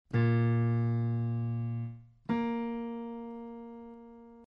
A top tone and a bottom tone, so to speak.
Nature's Interval - Piano
piano_Bb_octave.mp3